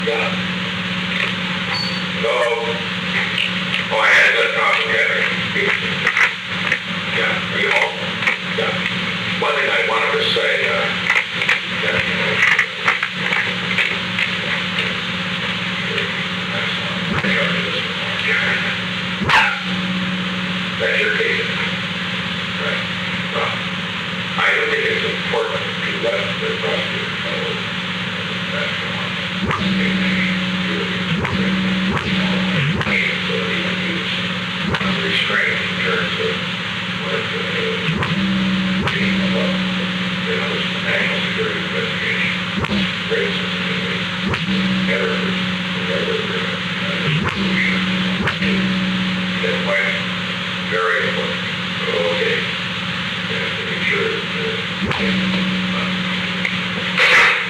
Secret White House Tapes
Location: Executive Office Building
The President talked with Richard G. Kleindienst.